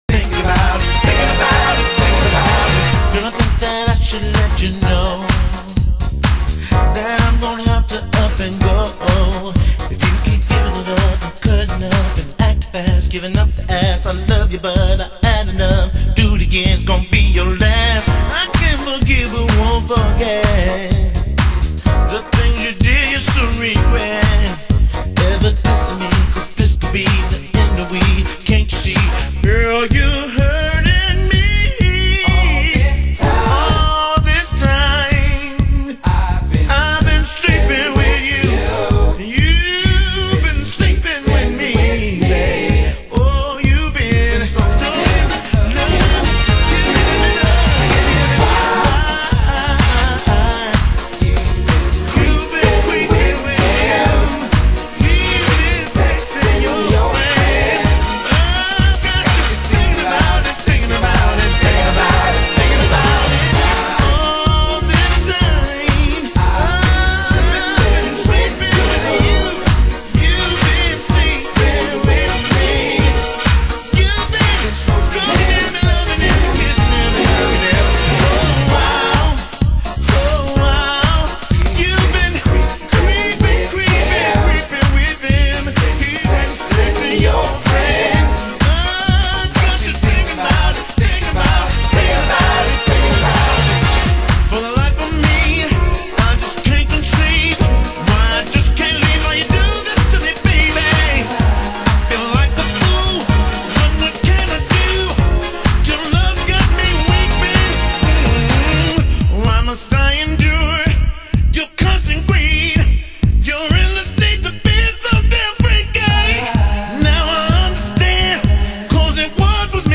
HOUSE MUSIC
盤　　盤質：少しチリパチノイズ有　　ジャケ：少しスレ有